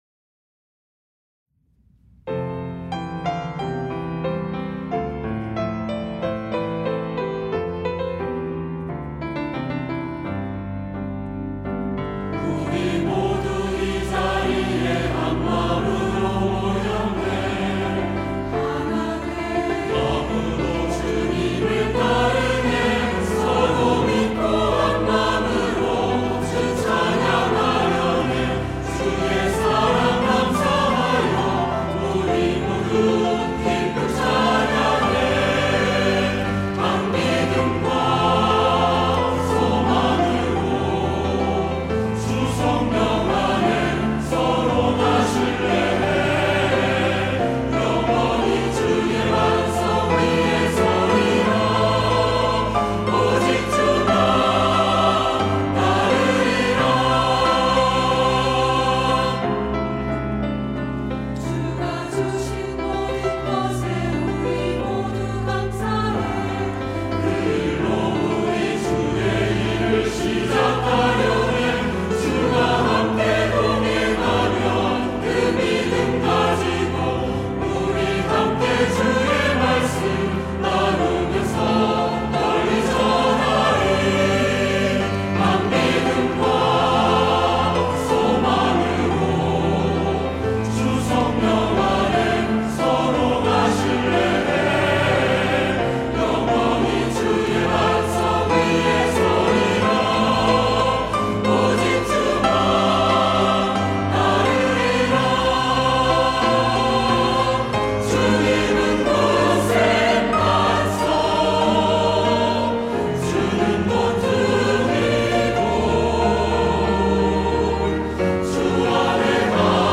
할렐루야(주일2부) - 한 믿음 가지고
찬양대